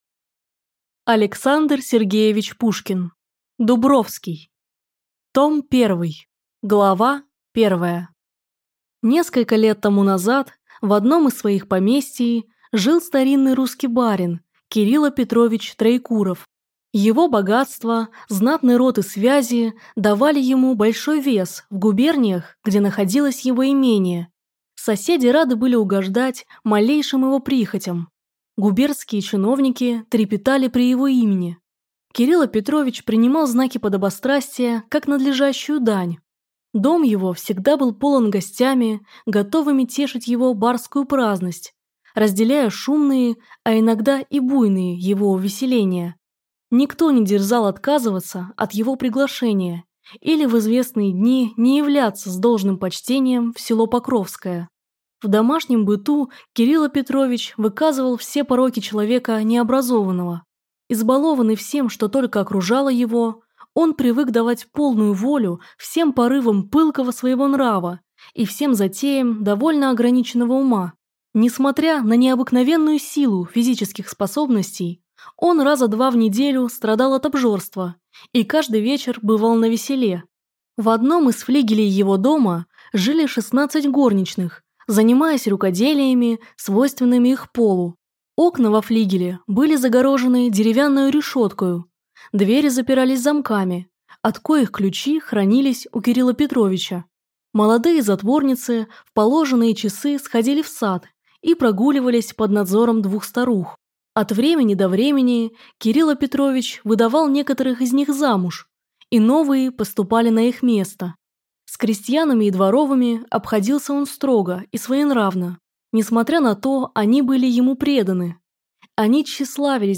Аудиокнига Дубровский | Библиотека аудиокниг